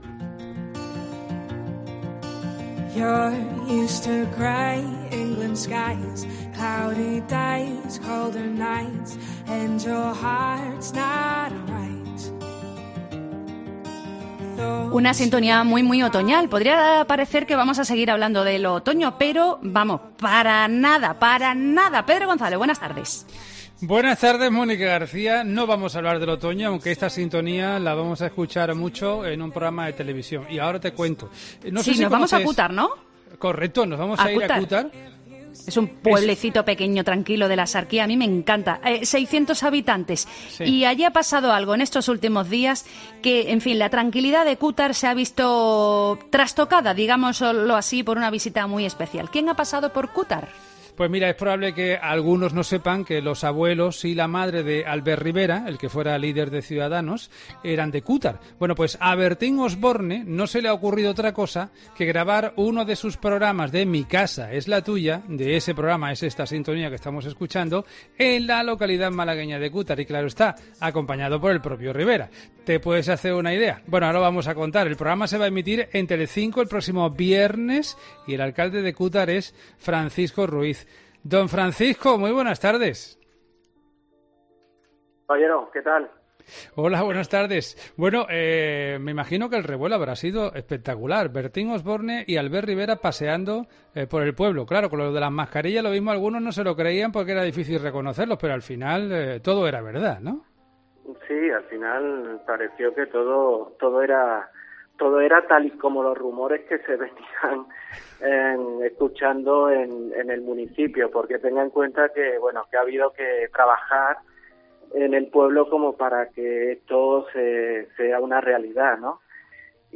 El alcalde de Cútar, Francisco Ruíz ha contado en 'Herrera en COPE Más Málaga' los pugares elegidos por la productora para grabar el programa.